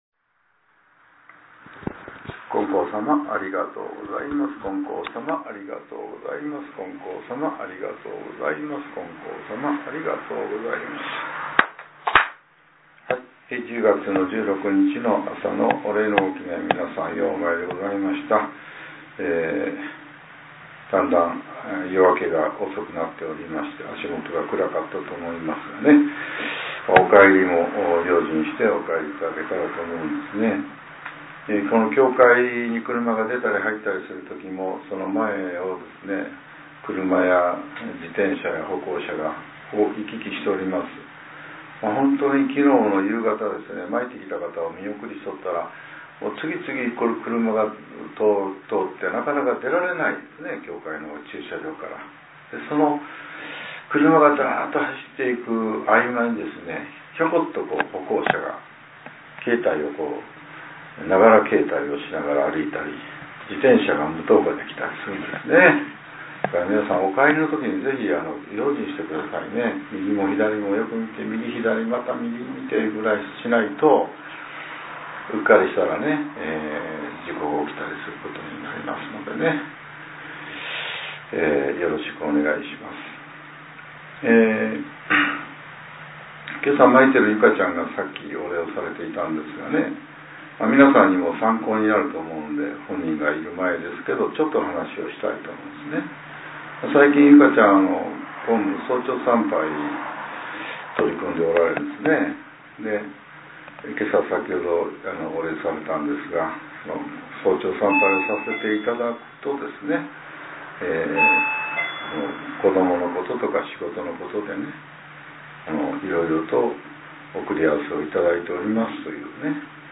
令和７年１０月１６日（朝）のお話が、音声ブログとして更新させれています。